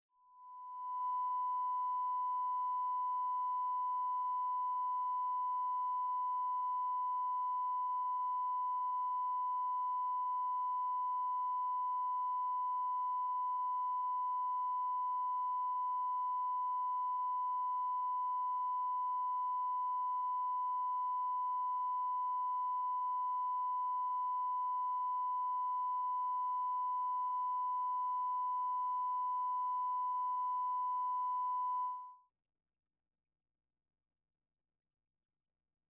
1000 Hz